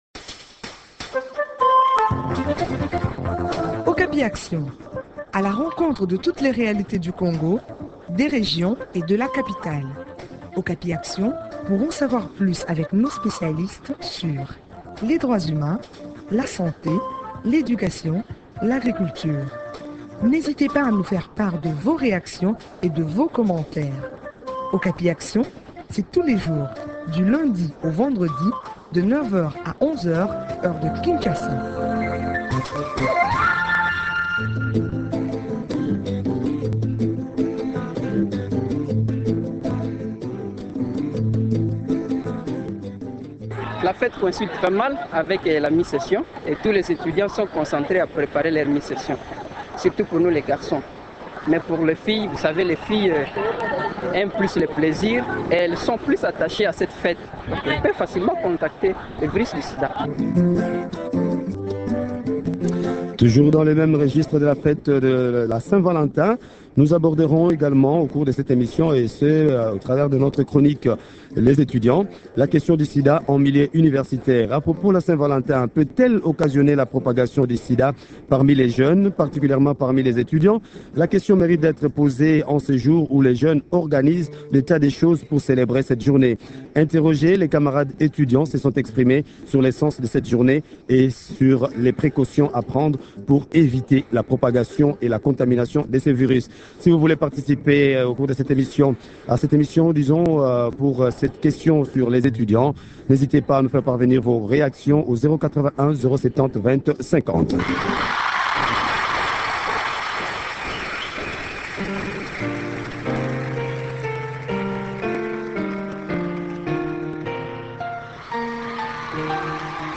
Interrogés, les étudiants se sont exprimés sur le sens de cette journée et sur les précautions à prendre pour éviter la propagation de cette maladie.